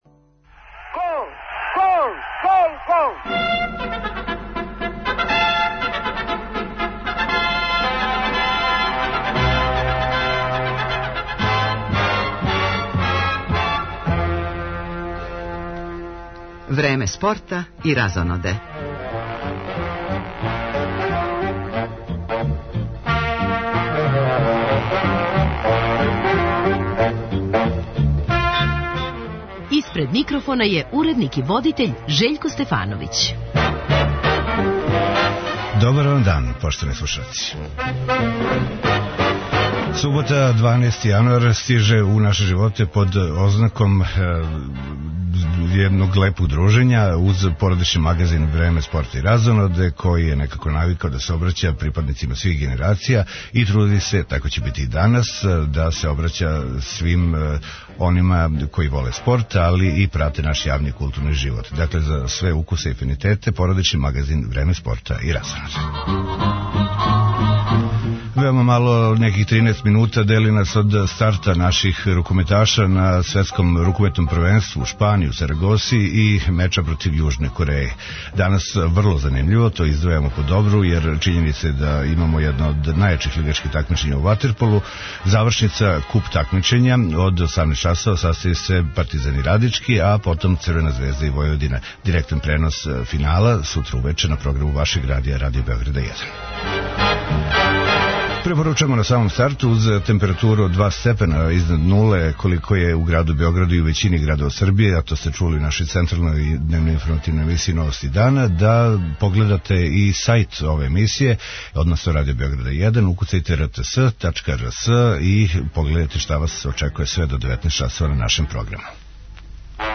Свој однос према радију и најзанимљивија искуства с њим моћи ће током емисије да изнесу и наши слушаоци.